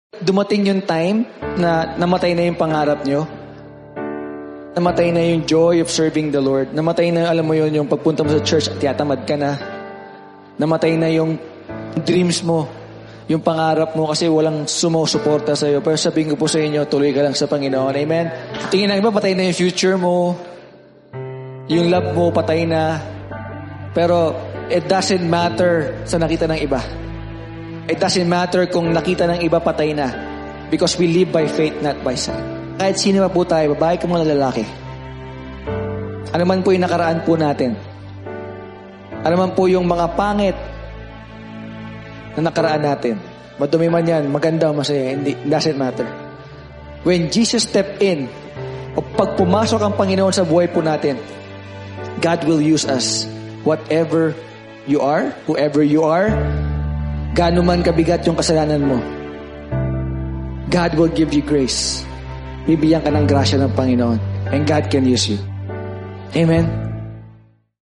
You can watch the whole preaching on our Facebook Page and Youtube Channel